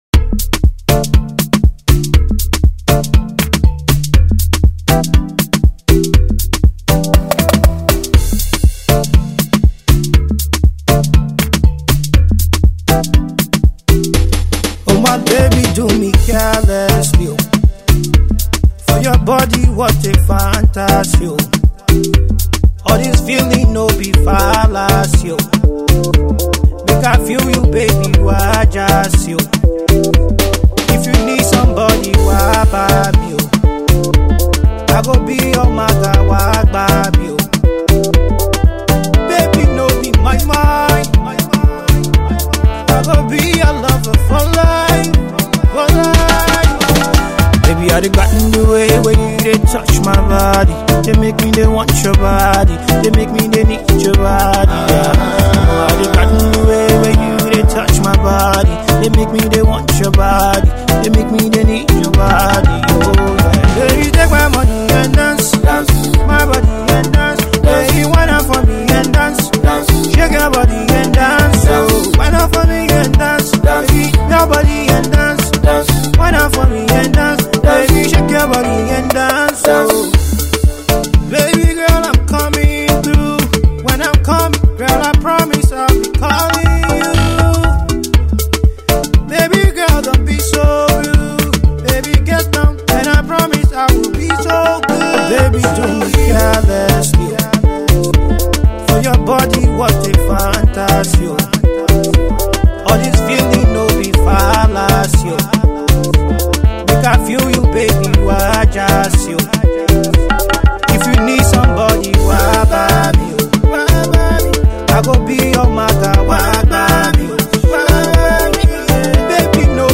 catchy up tempo pop track